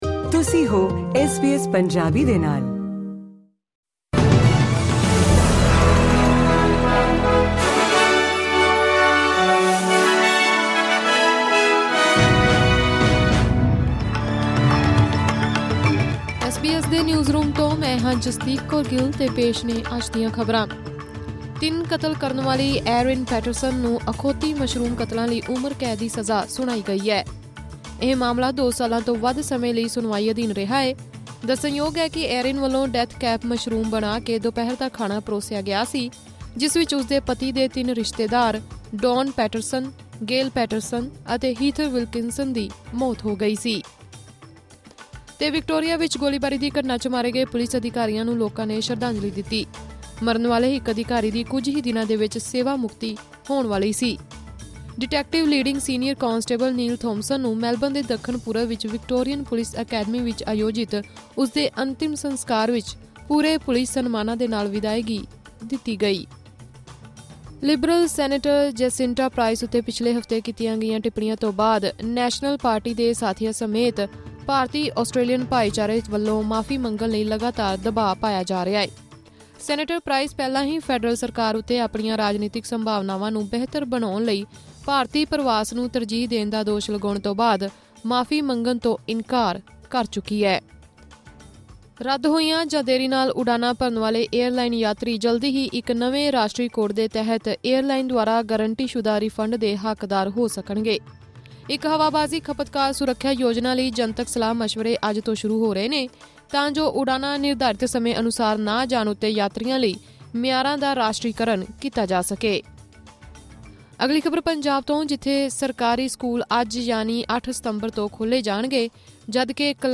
ਖ਼ਬਰਨਾਮਾ: ਖਾਣੇ 'ਚ ਜ਼ਹਿਰੀਲੇ ਮਸ਼ਰੂਮ ਖਵਾਉਣ ਵਾਲੀ ਪੈਟਰਸਨ ਨੂੰ ਹੋਈ ਉਮਰ ਕੈਦ